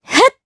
Laudia-Vox_Attack2_jp.wav